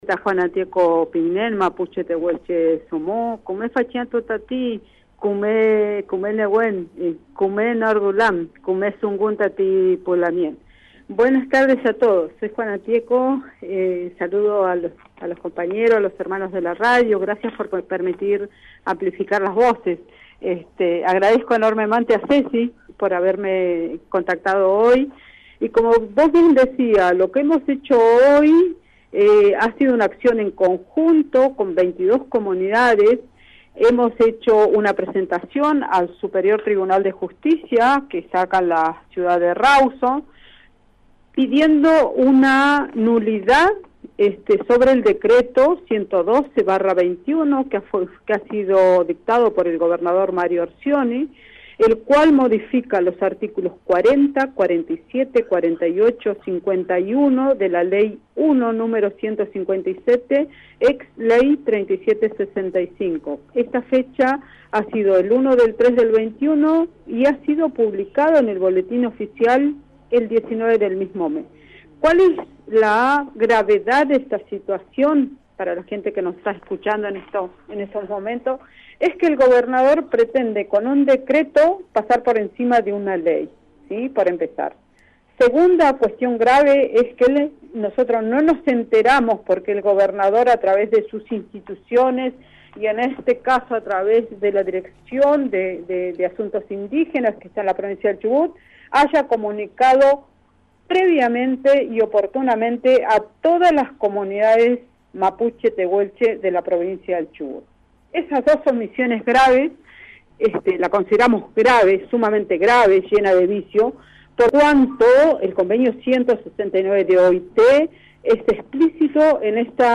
abogada mapuche, en diálogo con "Trabajo de hormiga" se manifestó sobre el las inconsistencias del decreto